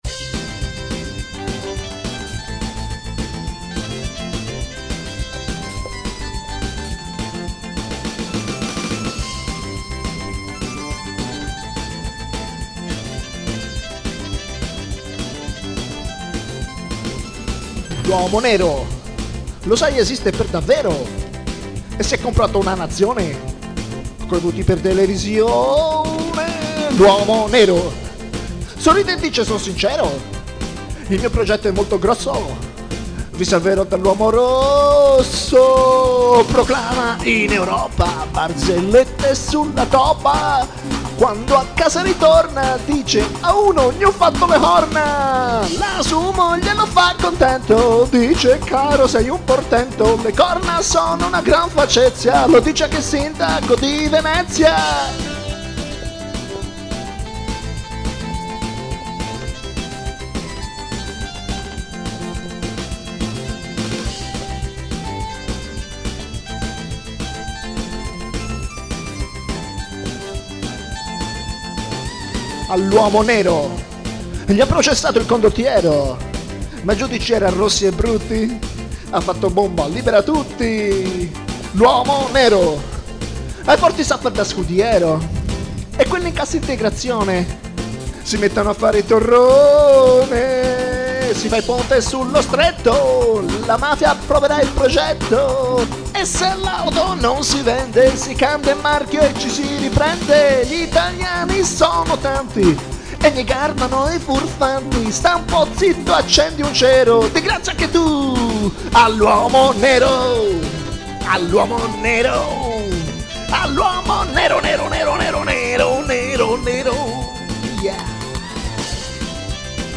Fare una canzone su Berlusconi e' insieme facilissimo e difficilissimo: facilissimo perche' basta raccontare la realta' che e' gia' abbastanza demenziale, difficilissimo perche'... cosa dire di nuovo? Cio' provato, se non altro gustatevi un altro po' di Ska!